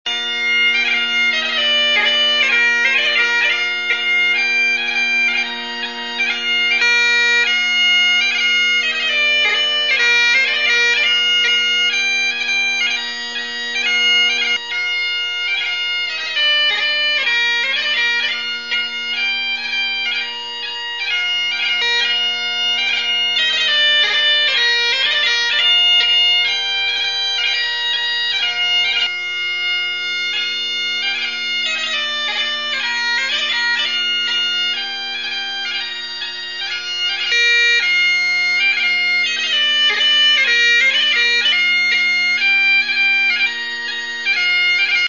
None, of the chanters were Bad, but some did excel in terms of balance and projection.
I also recorded the playing in a larger room, and about 6' from the mic.
I wanted to get away from that Dry Studio Sound, and give the sound a chance
to radiate, just as if you had recorded it, at an indoor contest.